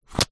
На этой странице собраны разнообразные звуки ящериц: от мягкого шуршания чешуи до резкого шипения.
Ящерица ловит добычу языком